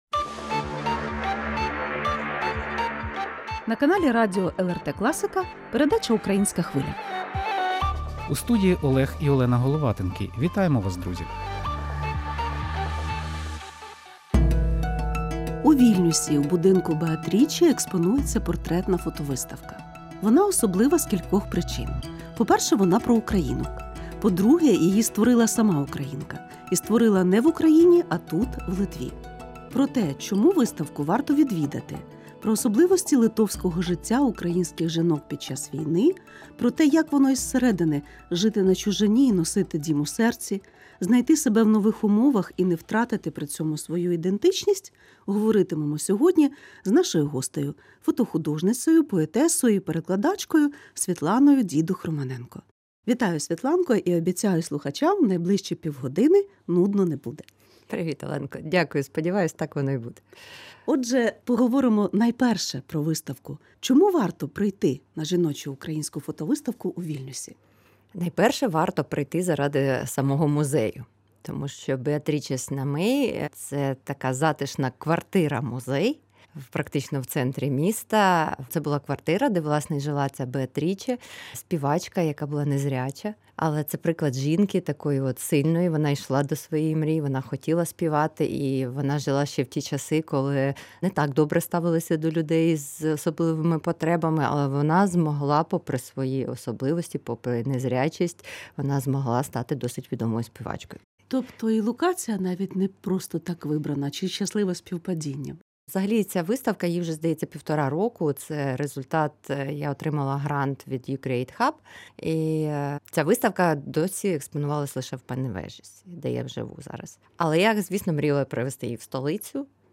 У передачі «Українська Хвиля» на радіо LRT Klasika – розмова з фотохудожницею